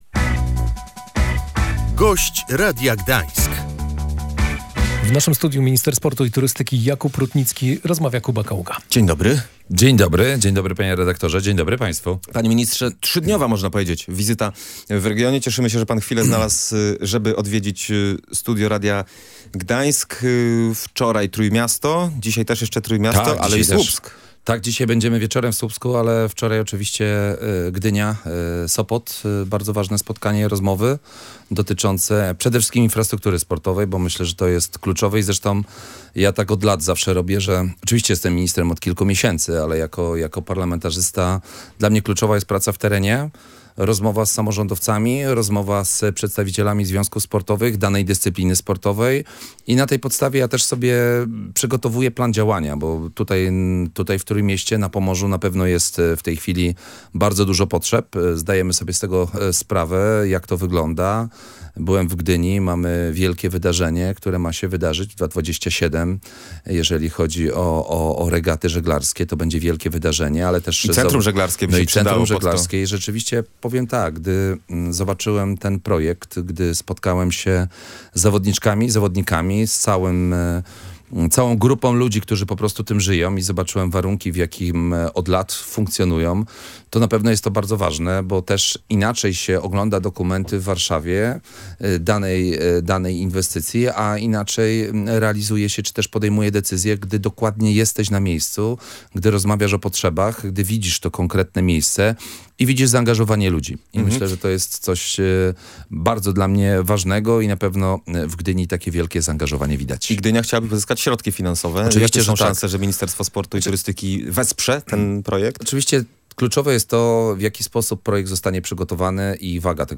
Tysiąc orlików w kraju jest w trakcie rewitalizacji - wskazał na antenie Radia Gdańsk Jakub Rutnicki, minister sportu i turystyki.